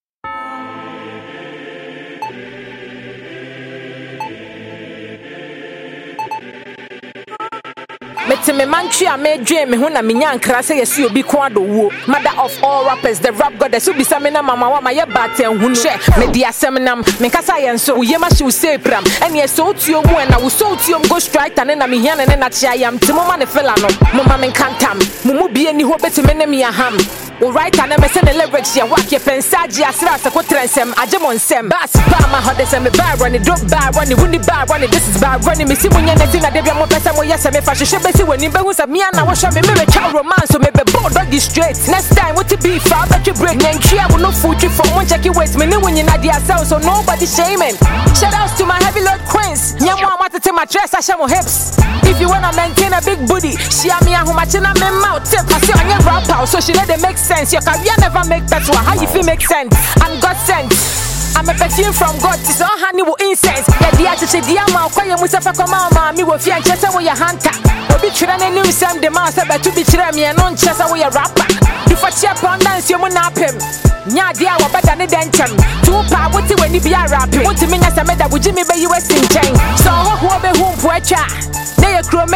Ghanaian rap goddess
diss tune